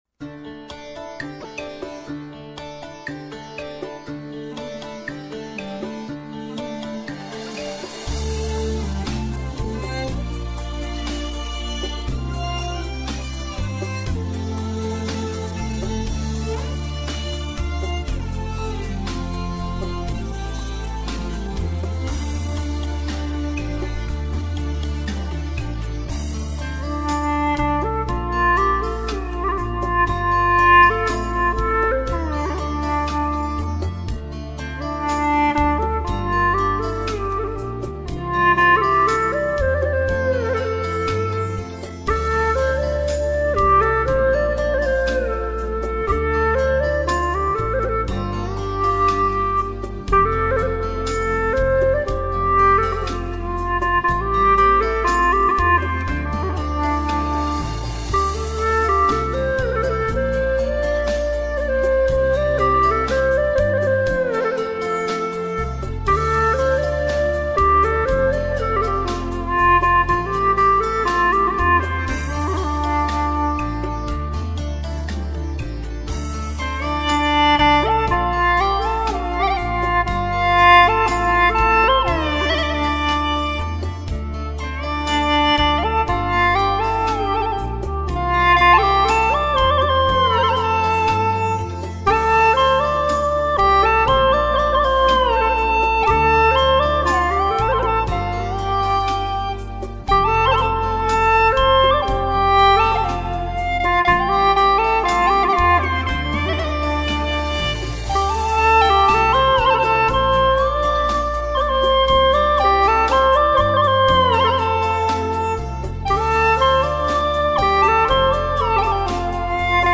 调式 : F 曲类 : 流行